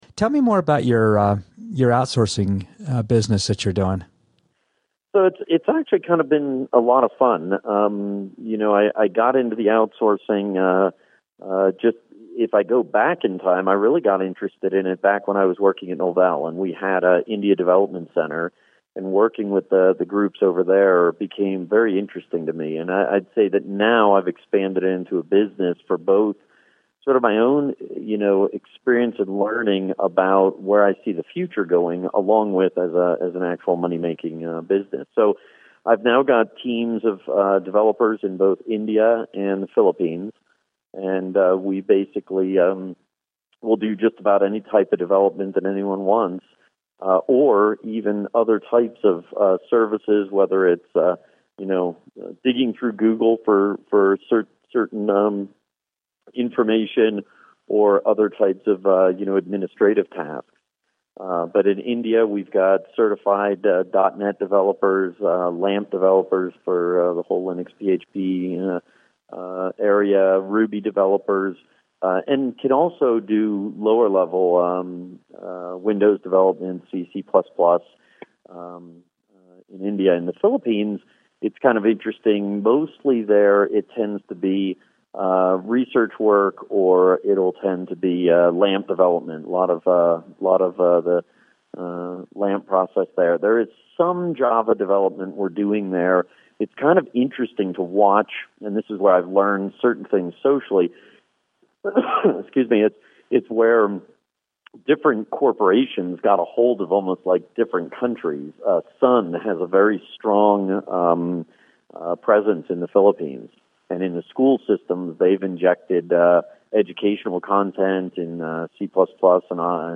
This is an excerpt from a longer interview .